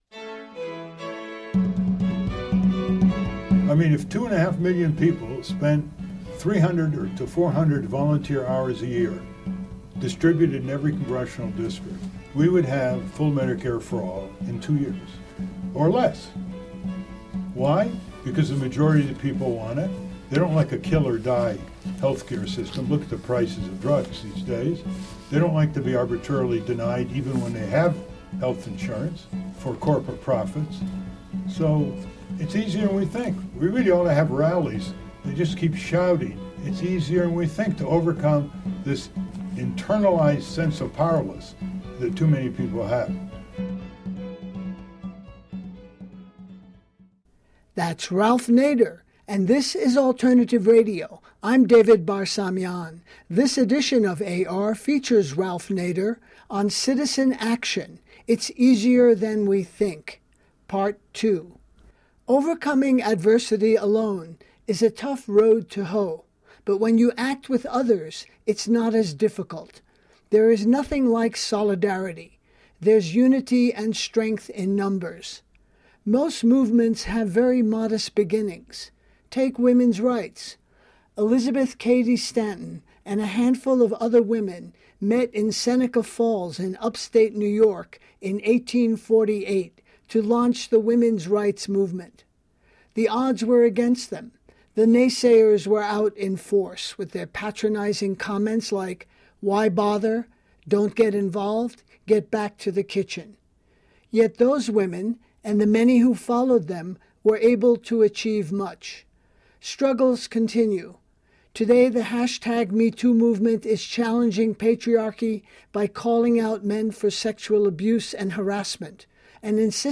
File Information Listen (h:mm:ss) 0:57:00 Ralph Nader Citizen Action: It's Easier than We Think Pt. 2 Download (0) NADR020_pt2_CitizenAction.mp3 34,218k 80kbps Mono Comments: Recorded in Winsted, CT on August 15, 2018.